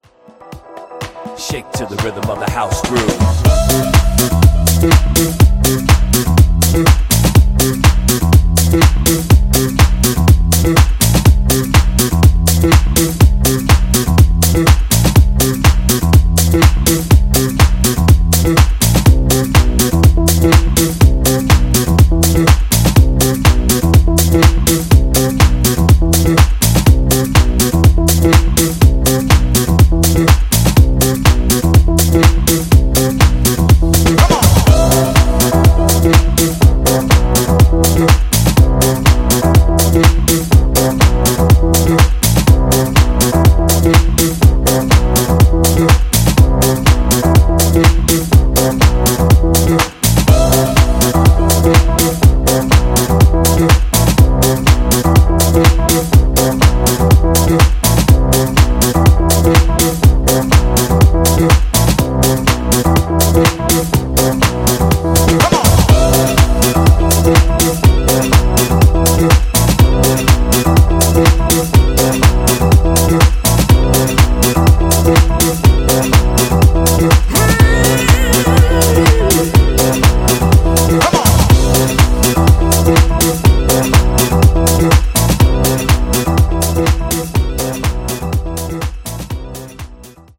フォーマット / 国名 / 他 12"inch / ITALY
ジャンル(スタイル) DISCO HOUSE